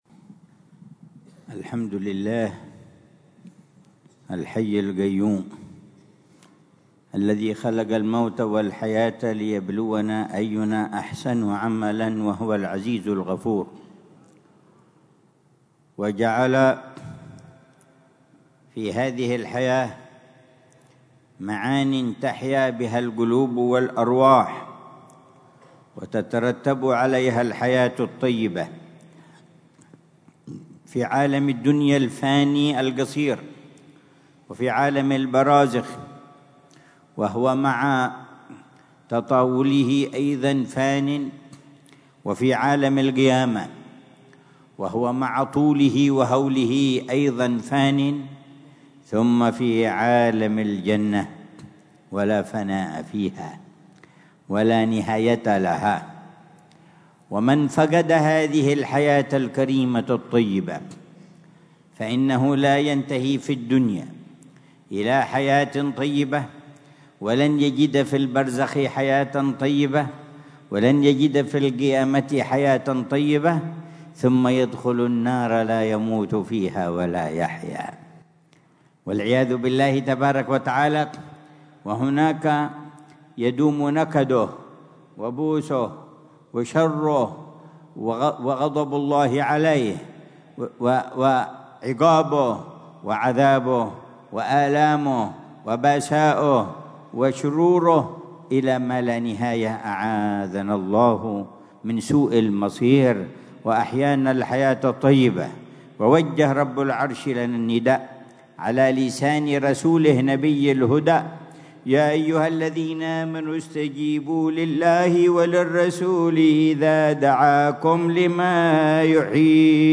محاضرة العلامة الحبيب عمر بن محمد بن حفيظ ضمن سلسلة إرشادات السلوك ليلة الجمعة 14 جمادى الثانية 1447هـ في دار المصطفى، بعنوان: